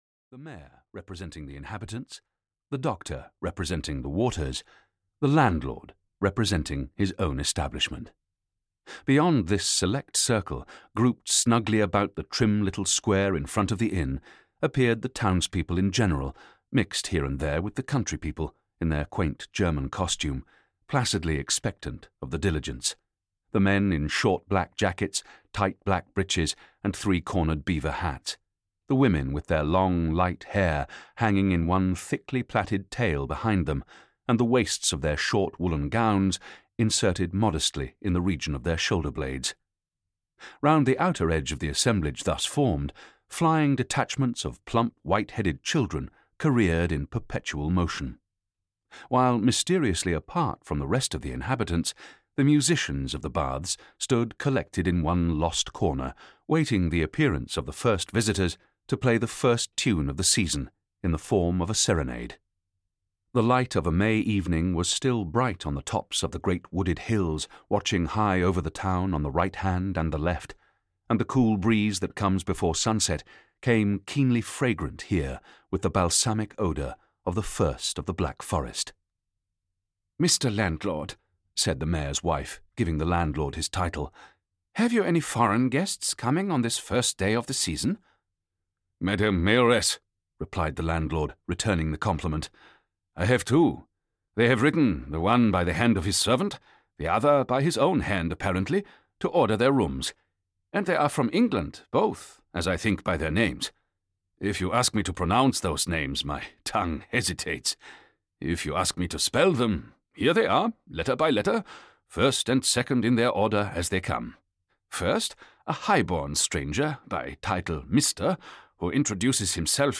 Armadale (EN) audiokniha
Ukázka z knihy